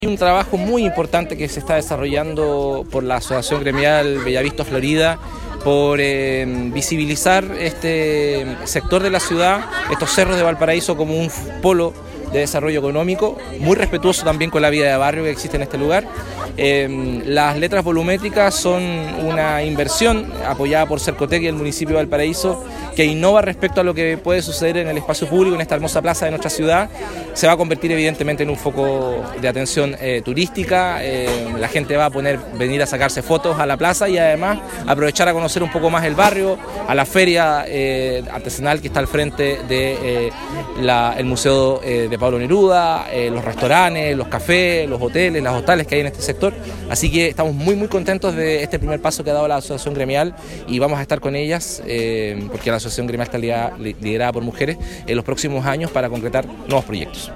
Por su parte, el alcalde Jorge Sharp relevó la iniciativa como aporte al turismo local.
Jorge-Sharp-Alcalde-de-Valparaiso.mp3